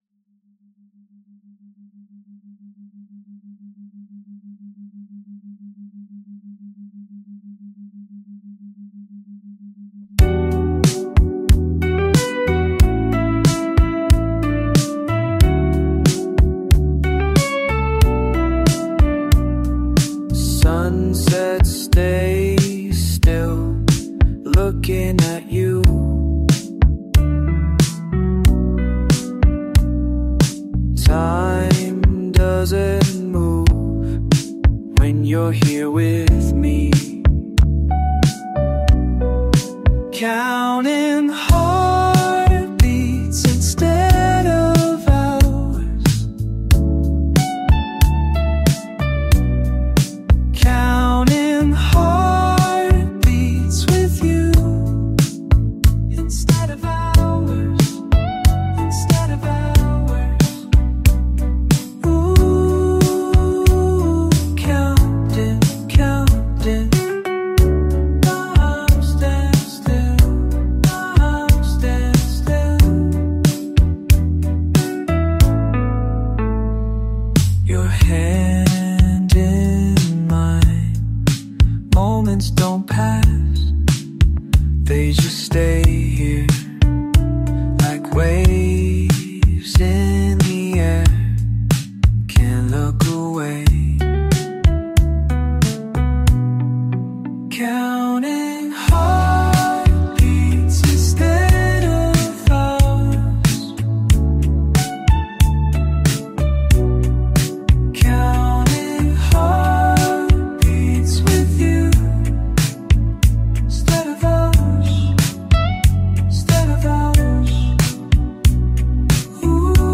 (🎧 Headphones Advisable)
lo-fi 1970s soft rock, binaural sound design